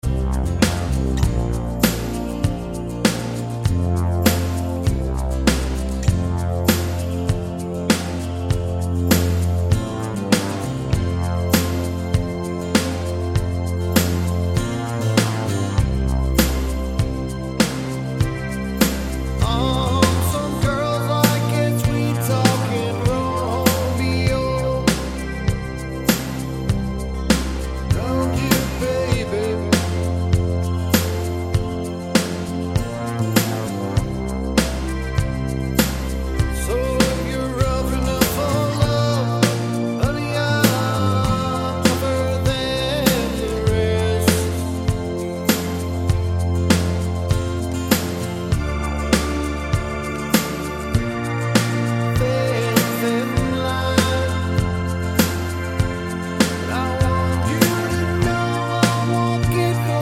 no harmonica Rock 4:40 Buy £1.50